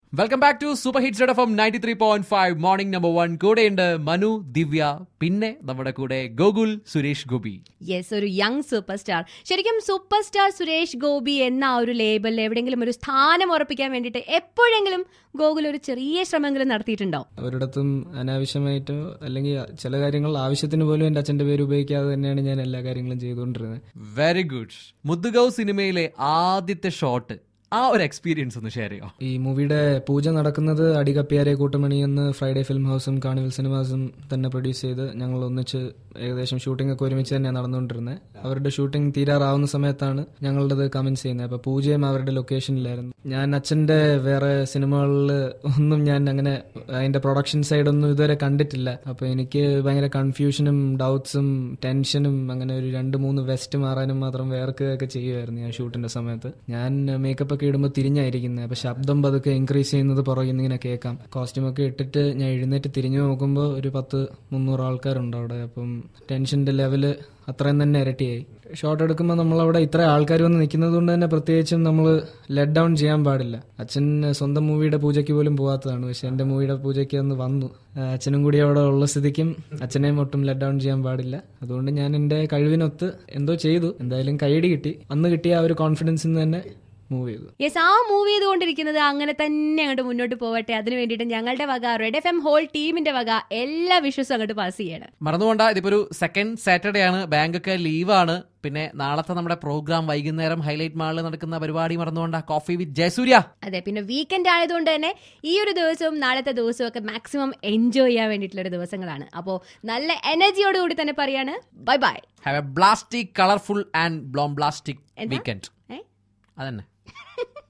GOKUL SURESH INTERVIEW